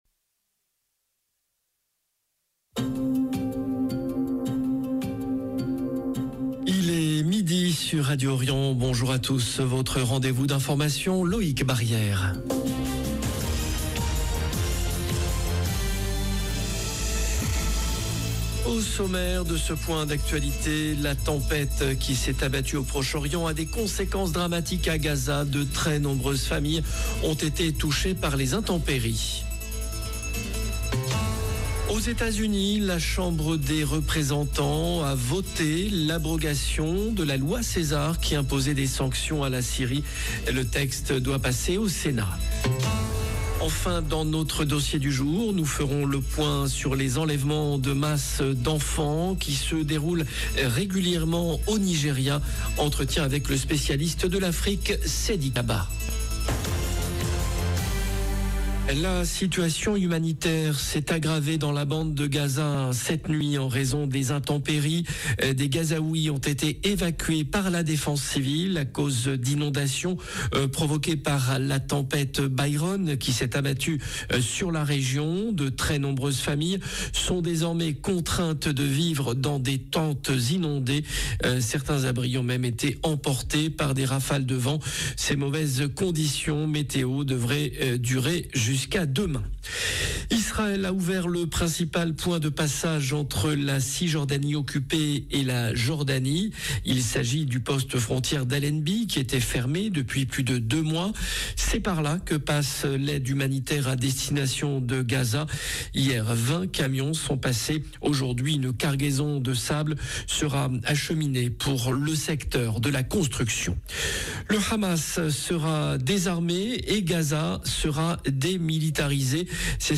Enfin dans notre dossier du jour, nous ferons le point sur les enlèvements de masse d’enfants qui se déroulent régulièrement au Nigéria. Entretien